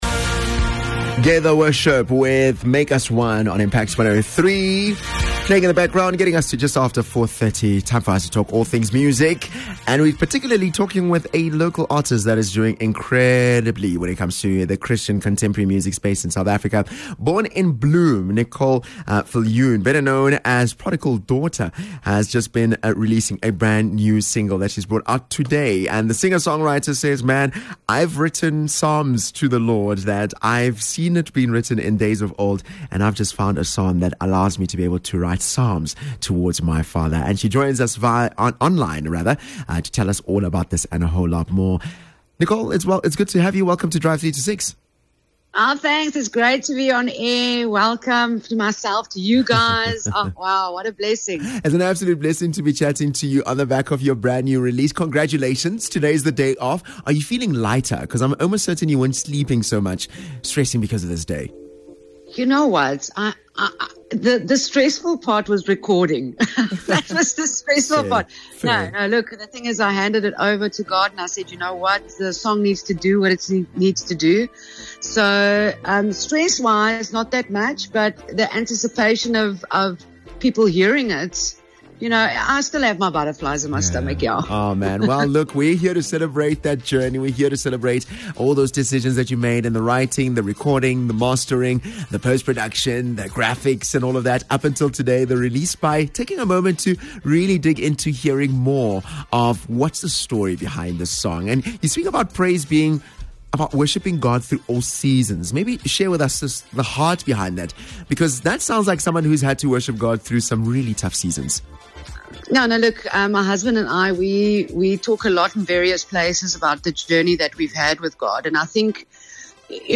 Interview with Local Artist